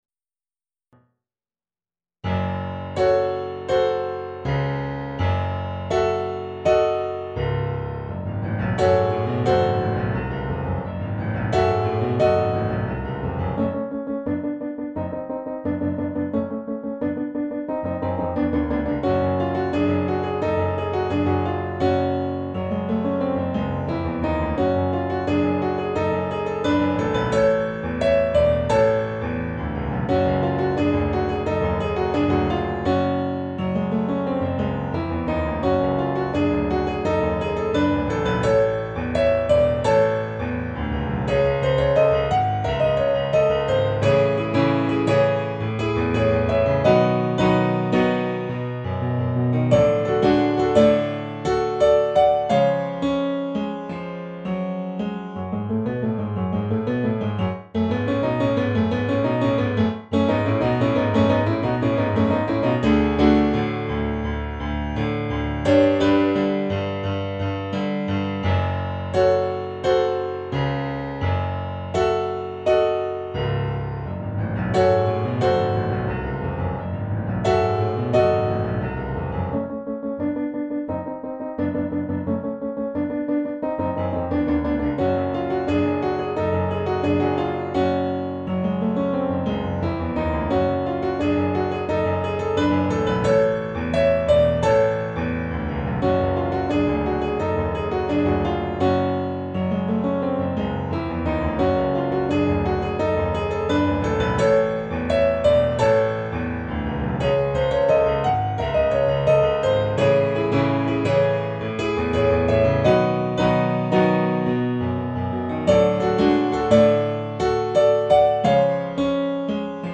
ピアノ楽譜付き
弾いてみた